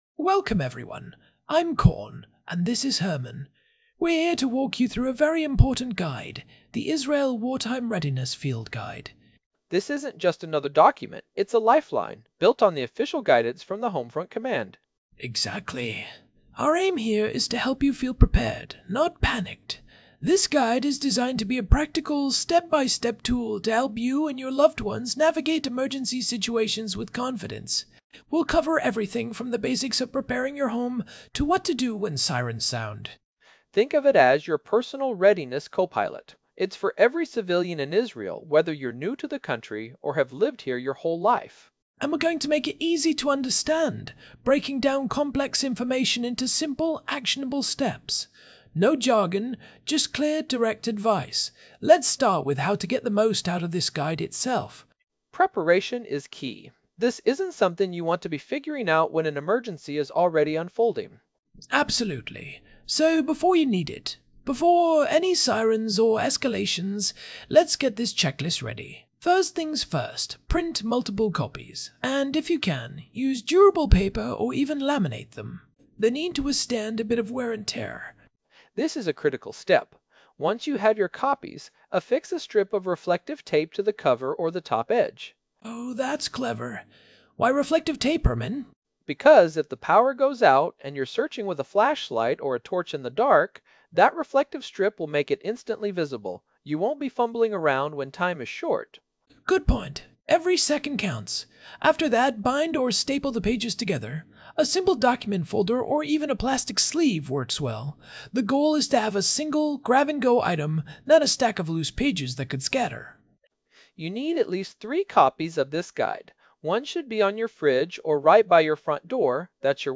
The complete Israel Wartime Readiness Field Guide read by Corn and Herman — 90 minutes of practical civilian preparedness guidance based on Home Front Command protocols.
AI-Generated Content: This podcast is created using AI personas.
TTS Engine chatterbox-regular
Hosts Herman and Corn are AI personalities.
israel-wartime-readiness-field-guide-audiobook.m4a